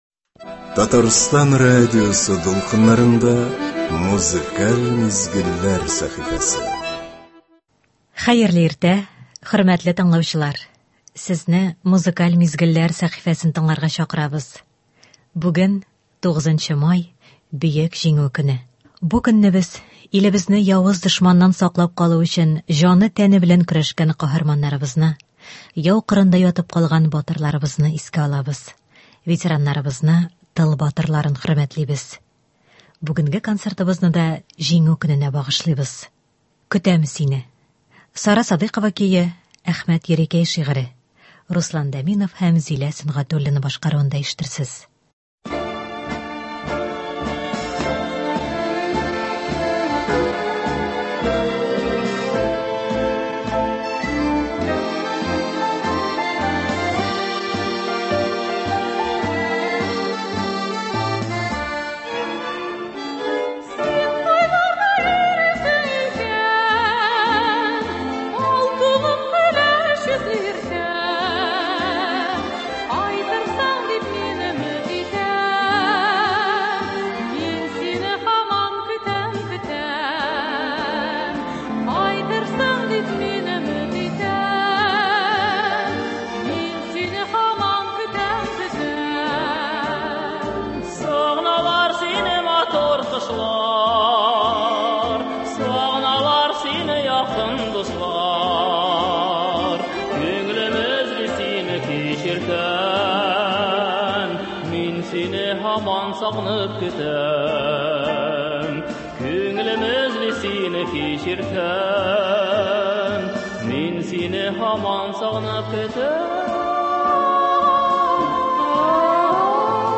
Бу көнне без илебезне явыз дошманнан саклап калу өчен җаны-тәне белән көрәшкән каһарманнарыбызны, яу кырында ятып калган батырларыбызны искә алабыз, ветераннарыбызны, тыл батырларын хөрмәтлибез. Бүгенге концертыбызны да Җиңү көненә багышлыйбыз.